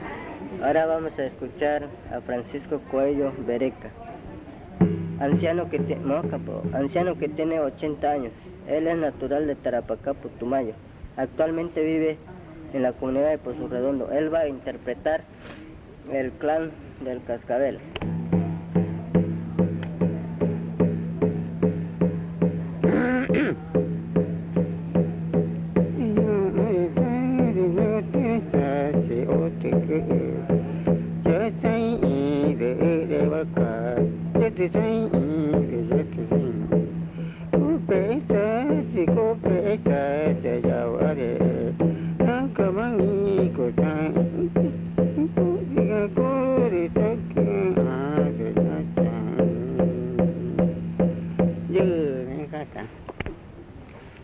Canto del clan Cascabel
Pozo Redondo, Amazonas (Colombia)
El abuelo usa el tambor mientras realiza su canto.
The elder uses a drum while singing.